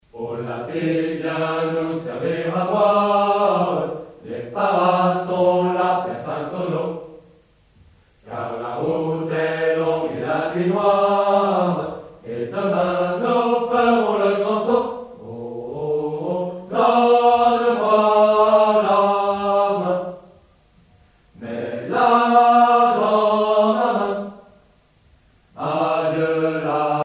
gestuel : à marcher
circonstance : militaire
Pièce musicale éditée